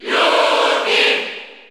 Category: Crowd cheers (SSBU) You cannot overwrite this file.
Ludwig_Cheer_Spanish_NTSC_SSB4_SSBU.ogg